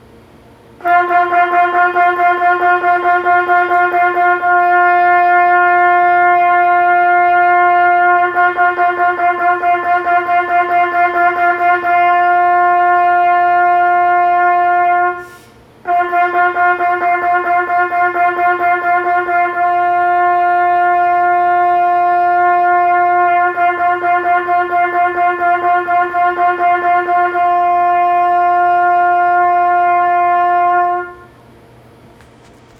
ここでスムーズに息が通ってる状態でのタンギングの音を聞いてください。
【スムーズなタンギング】
タンギング-C01.wav